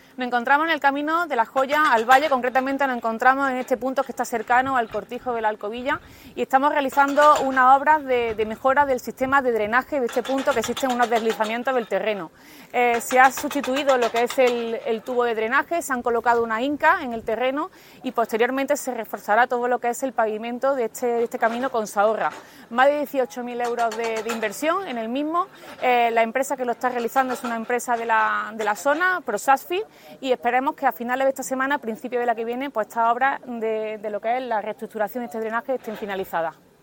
La teniente de alcalde Teresa Molina destaca la importrante mejora que van a conllevar estas obras para los usuarios de dicha vía y la zona sur de El Torcal.
Cortes de voz